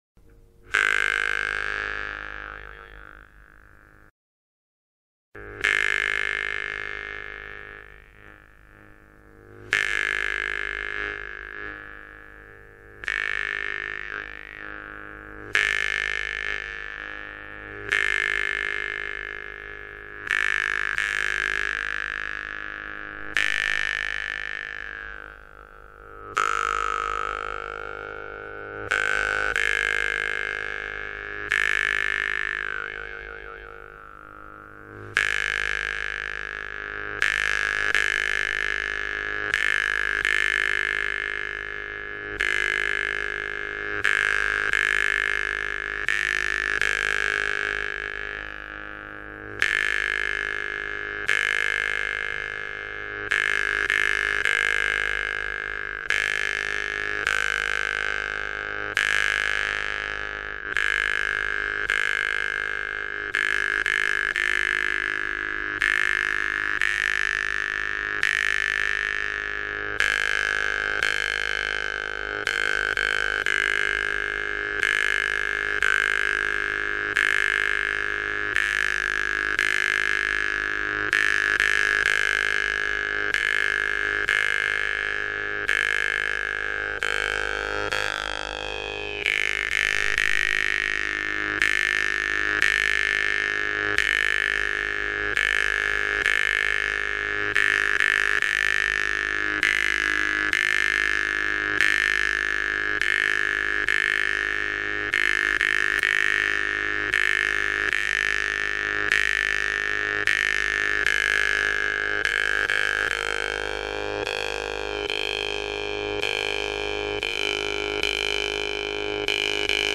vargan